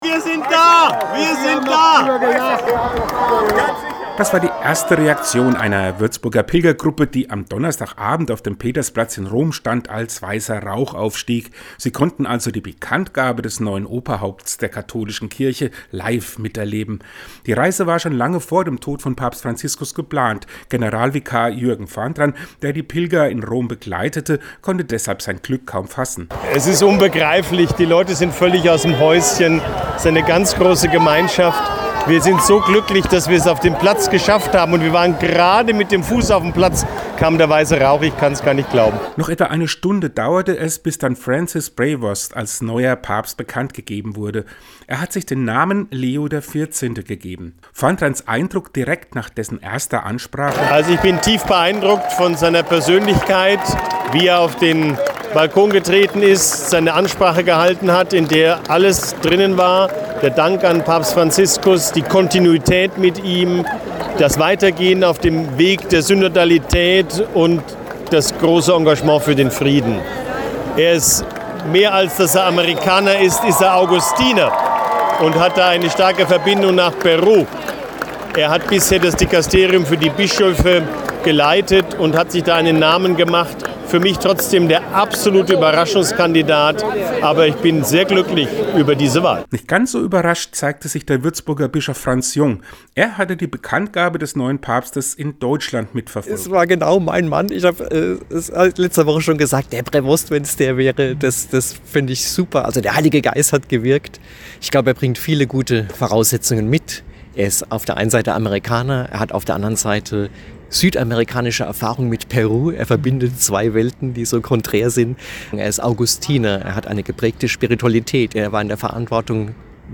Wir haben sie und den Würzburger Bischof Franz Jung nach ihren Eindrücken gefragt.